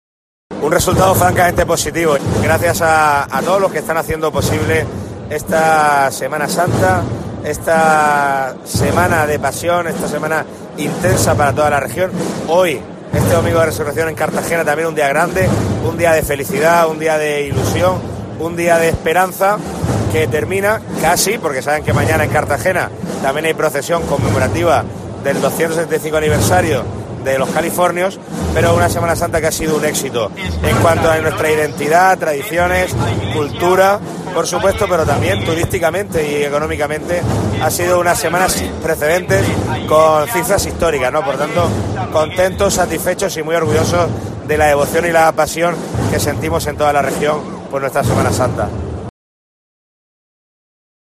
El presidente del Gobierno regional, Fernando López Miras, participó esta mañana en Cartagena en la procesión de domingo de Resurrección de la Real e Ilustre Cofradía de Nuestro Padre Jesús Resucitado, donde calificó como "éxito" la Semana Santa que hoy llega a su fin.